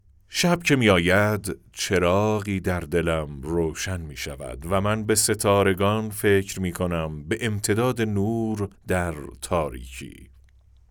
نریشن شب یلدا